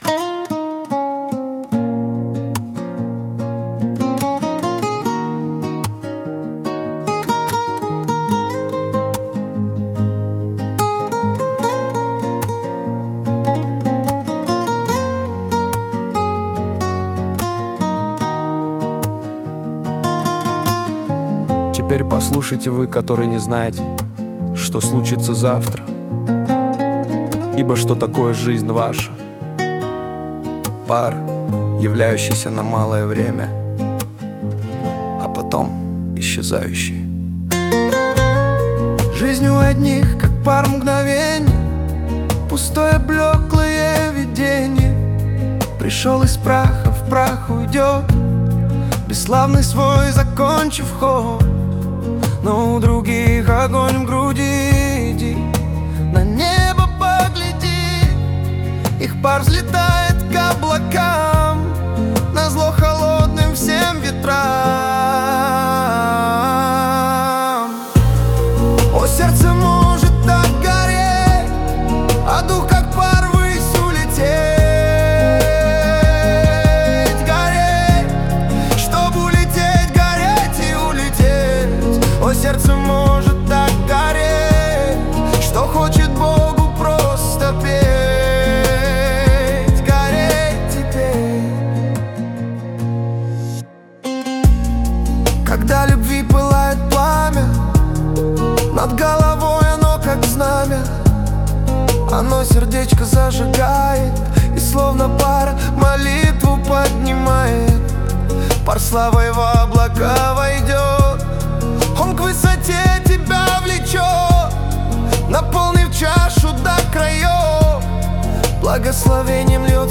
песня ai
432 просмотра 1352 прослушивания 104 скачивания BPM: 73